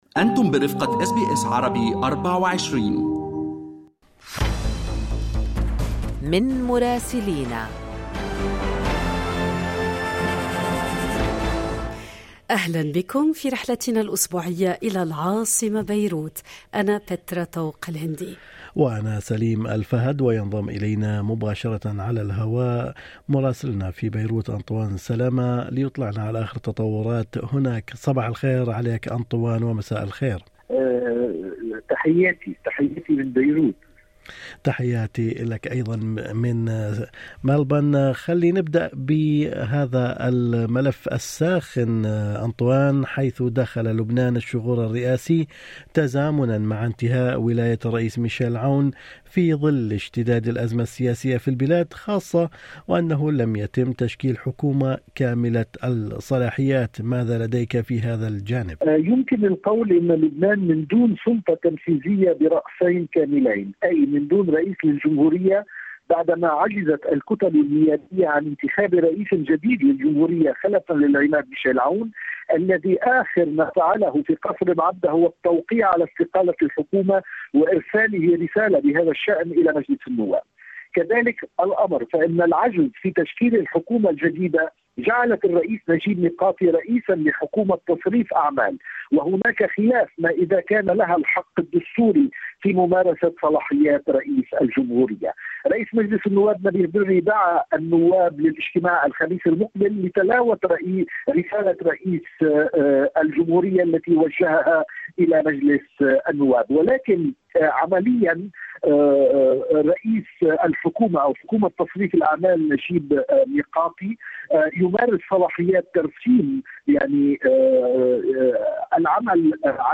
يمكنكم الاستماع إلى تقرير مراسلنا في العاصمة بيروت بالضغط على التسجيل الصوتي أعلاه.